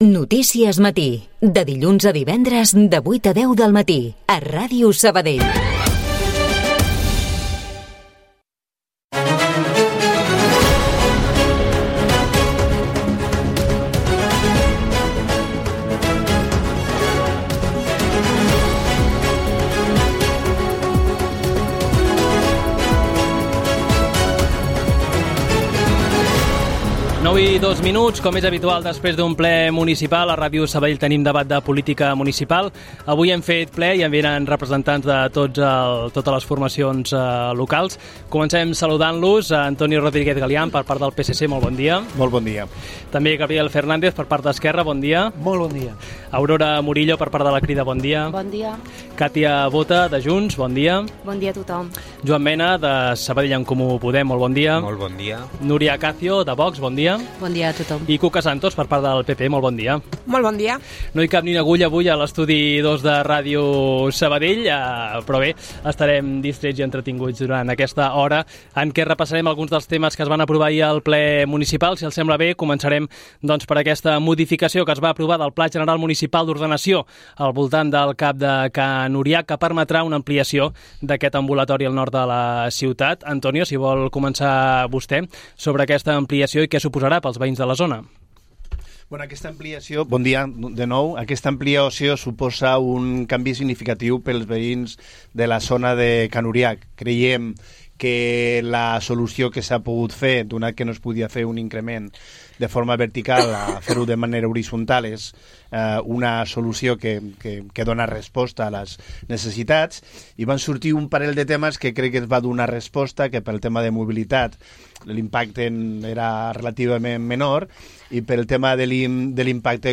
El 8-M a Ràdio Sabadell: Debatem sobre feminisme amb regidores de tots els partits a l'Ajuntament